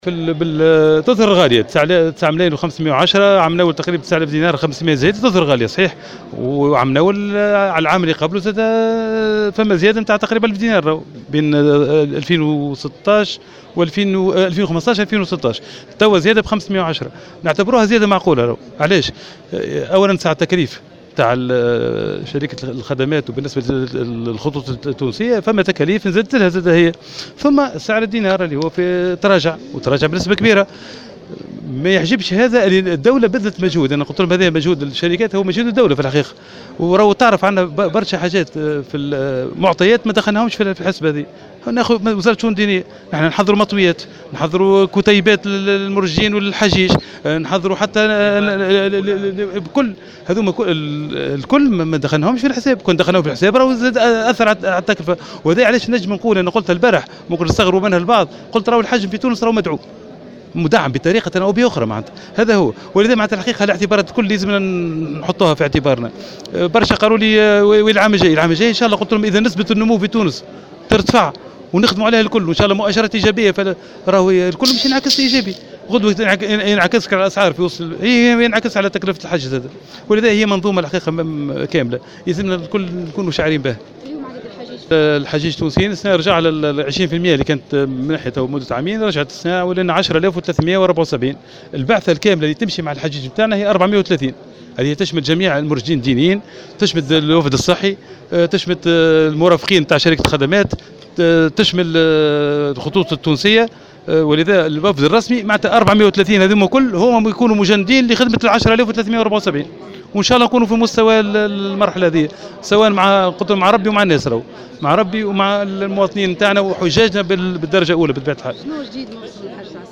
وأضاف في تصريحات صحفية على هامش زيارة لولاية مدنين، أن الزيادة بلغت نحو 500 دينار مقابل قرابة ألف دينار الموسم الماضي ملاحظا أن الزيادة تتلاءم وتكاليف شركة الخدمات والخطوط التونسية بالإضافة إلى عامل تراجع سعر الدينار.